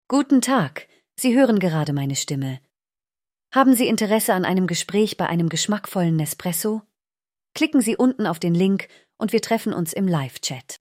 Stimmprofil
Auf Basis meines Persönlichkeitsprofils klinge ich warm, kultiviert und klangvoll – und immer gelassen.
Stimme weiblich
Nespresso-Stimmprofil-DE-weiblich.mp3